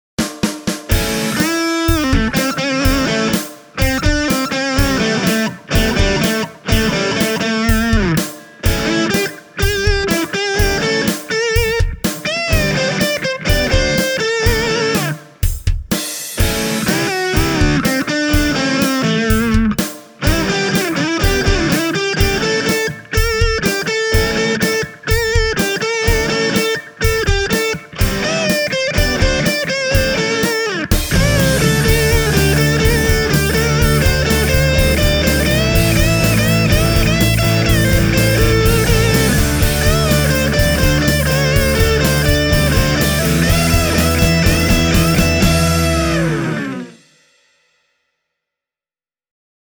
BLUES demo
Rhythm guitars: Fender Telecaster (left channel) & Epiphone Casino (right channel)
Lead guitar: Fender Stratocaster